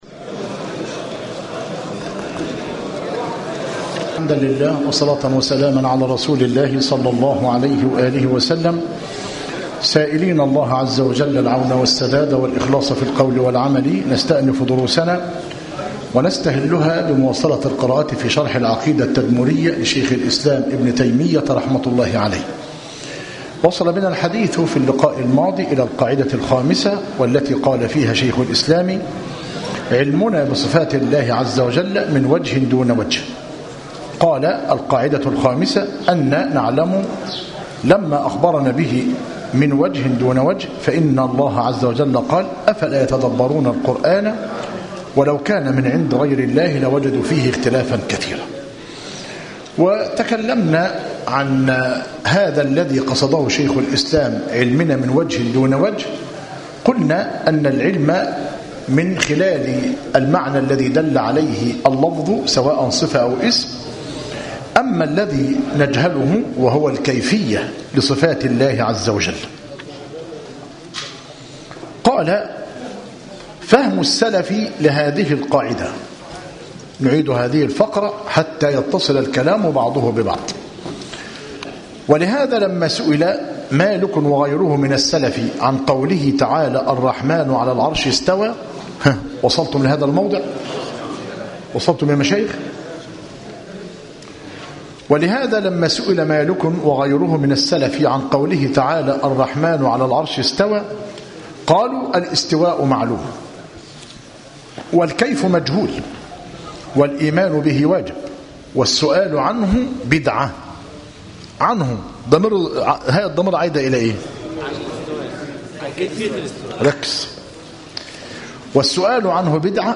التعليق على كتاب التوضيحات الأثرية لمتن الرسالة التدمرية جمع وترتيب فخر الدين بن الزبير بن علي المحسَي - مسجد التوحيد - ميت الرخا - زفتى - غربية - المحاضرة الثانية والثلاثون - بتاريخ 9- جماد آخر- 1437هـ الموافق 18 - مارس- 2016 م